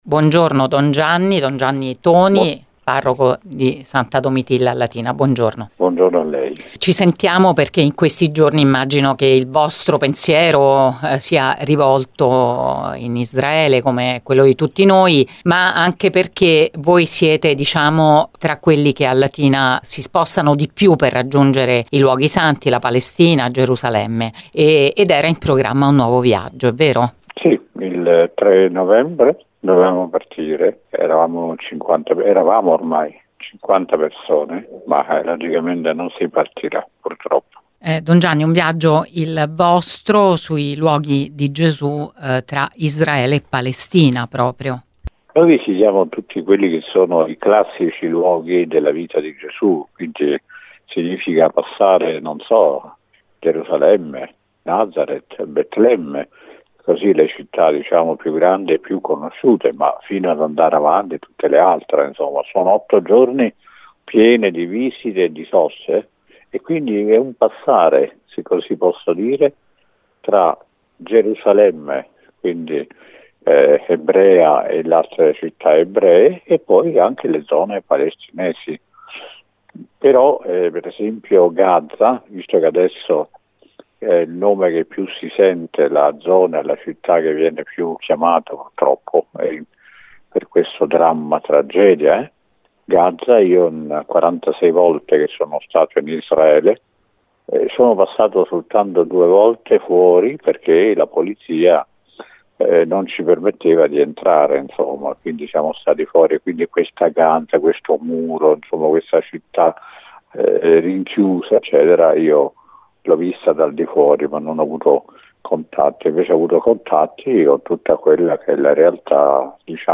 poi la sua voce si rompe